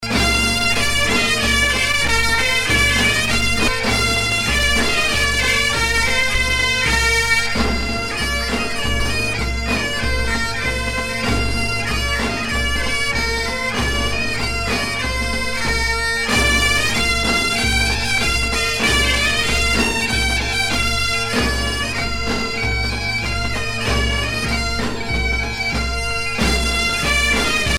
gestuel : à marcher
circonstance : fiançaille, noce